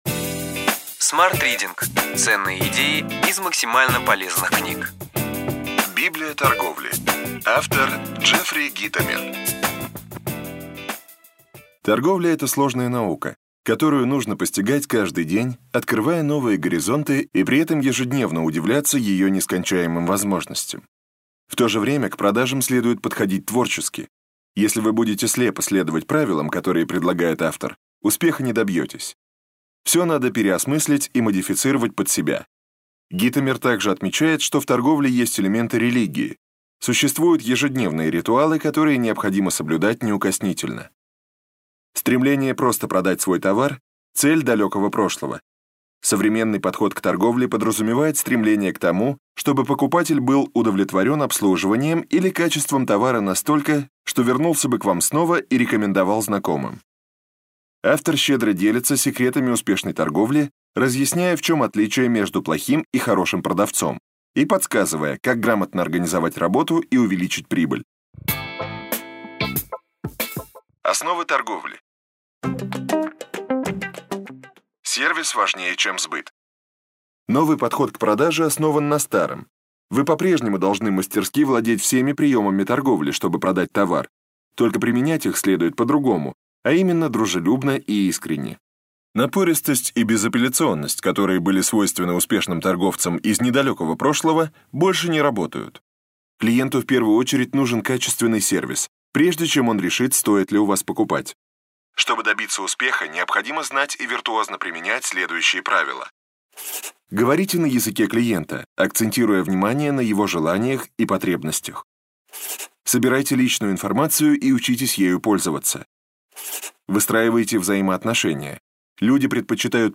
Аудиокнига Ключевые идеи книги: Библия торговли. Джеффри Гитомер | Библиотека аудиокниг